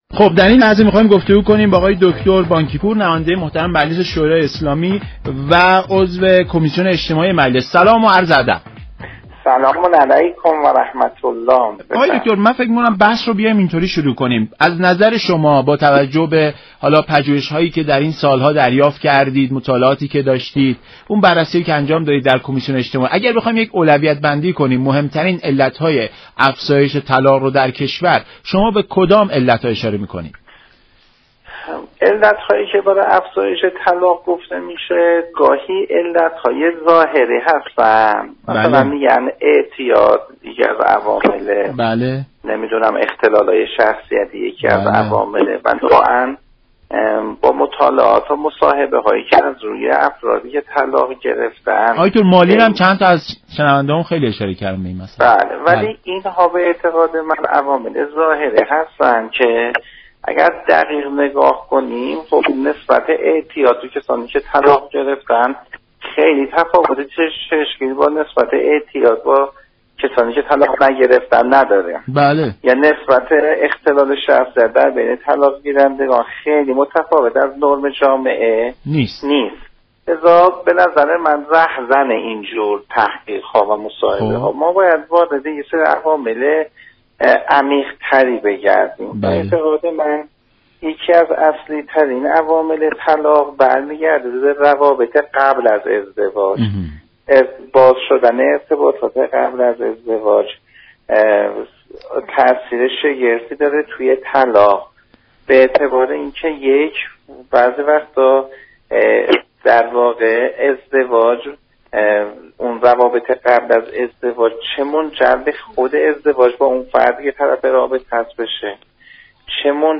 عضو كمیسیون اجتماعی مجلس شورای اسلامی در برنامه ایران امروز گفت: در ازدواج افراد به جای آنكه شناخت درستی از یكدیگر داشته باشند، تابع احساسات خود می‌شوند.